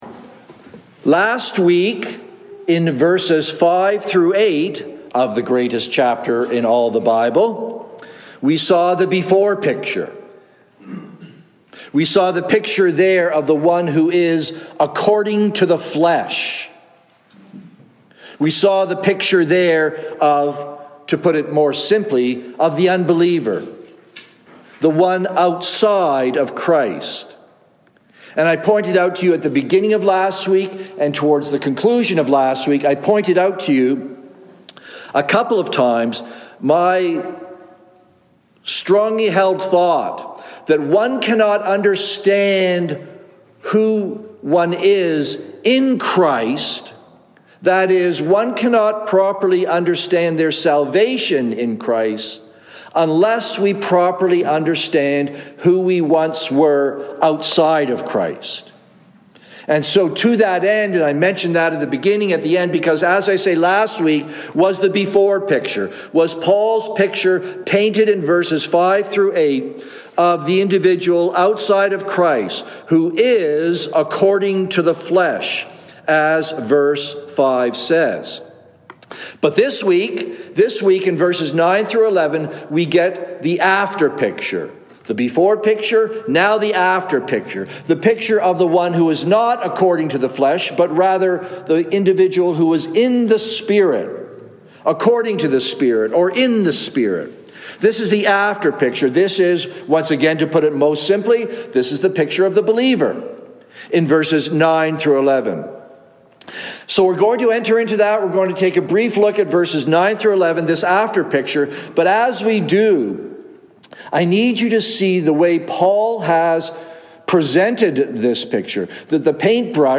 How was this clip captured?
“The Sprit is Life” (Evening Service)